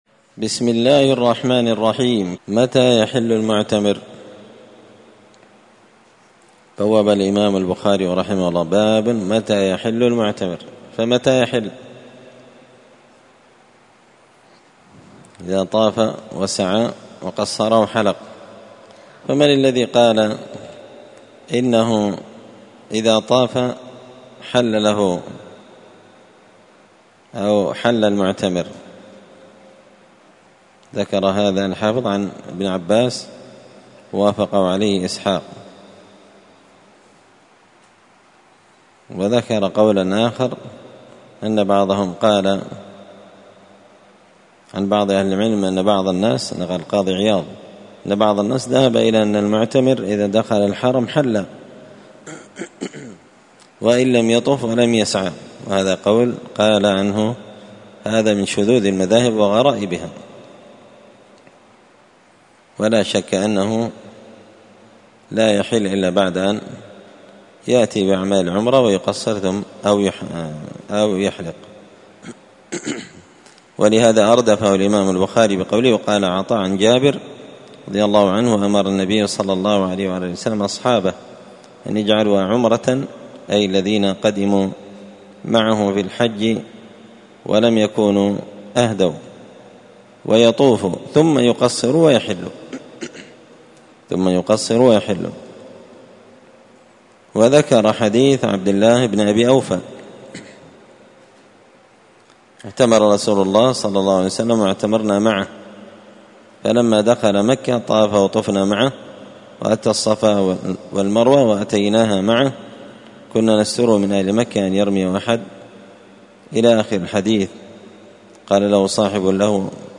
الجمعة 17 محرم 1445 هــــ | الدروس، شرح صحيح البخاري، كتاب العمرة | شارك بتعليقك | 113 المشاهدات
مسجد الفرقان قشن المهرة اليمن